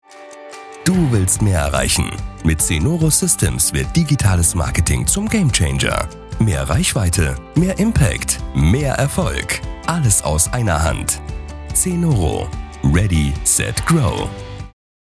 • Eigenes Tonstudio
Imagefilm
dynamisch motivierend
Imagefilm-dynamisch-motivierend.mp3